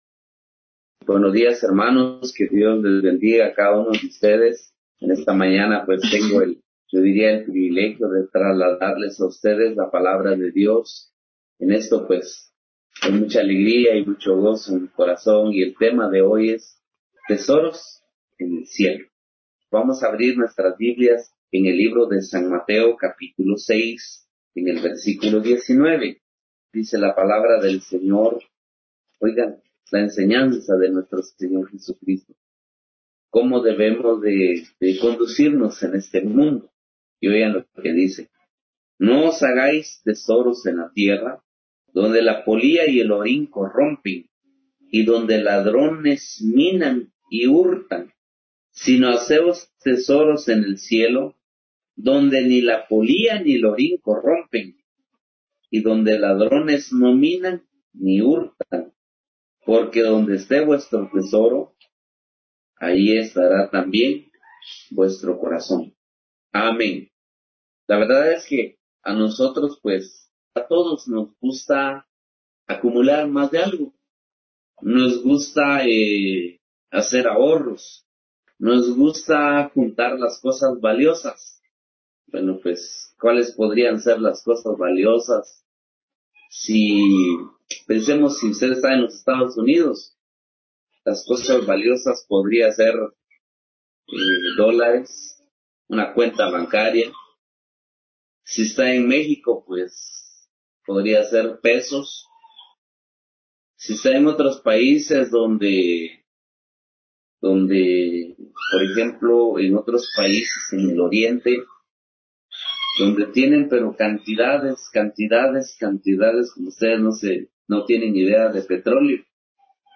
Series: Servicio General